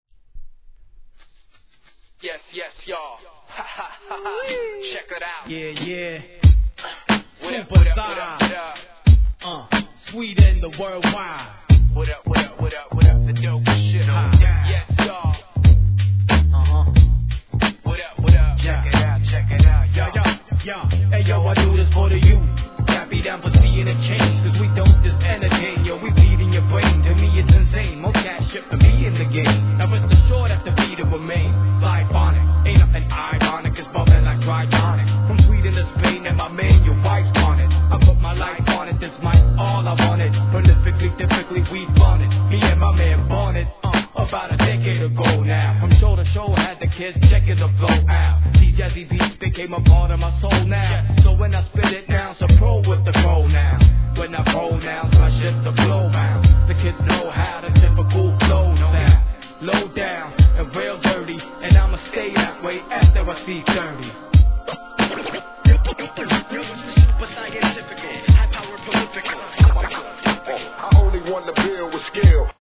HIP HOP/R&B
極上のジャジー＆メロウなヴァイブスを放つスウェーデン4人組クルー!